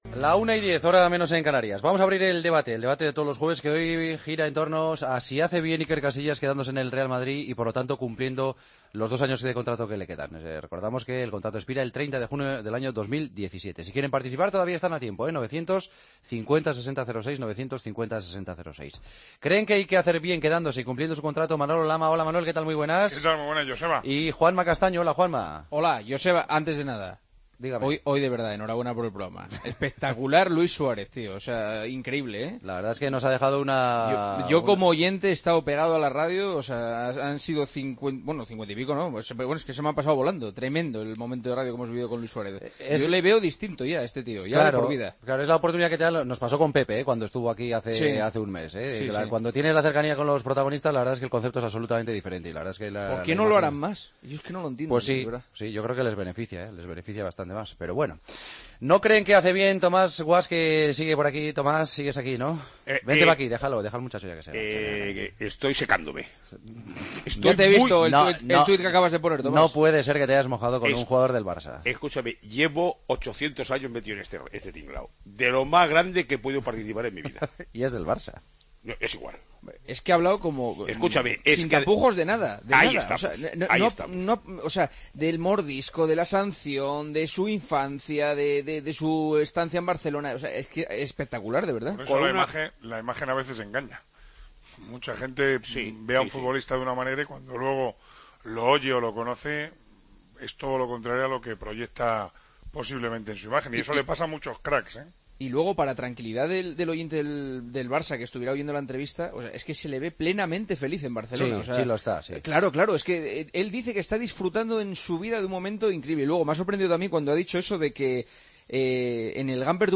El debate de los jueves: ¿Hace bien Iker Casillas quedándose en el Real Madrid hasta terminar su contrato?